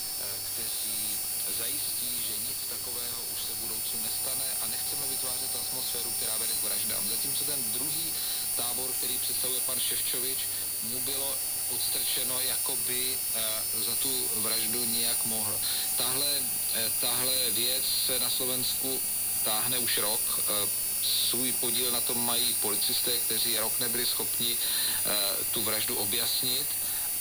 Mit Hilfe des Verstärkers konnte nun endlich der tschechische Sender auf 539 kHz gehört werden, mit der Diode und auch mit dem Detektor. Der Unterschied ist nicht so groß, kleiner als der Schwund des Übertragungswegs
mit AA118 Germaniumdiode